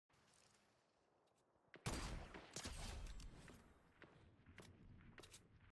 OG Fortnite Headshot Snipe sound effects free download
OG Fortnite - Headshot Snipe From Height